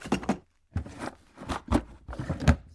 随机的" 抽屉木头旧的打开关闭快的笨拙的粘性捕捉器
描述：抽屉木老打开关闭快速尴尬粘cat.wav
Tag: 打开 关闭 抽屉 快速 别扭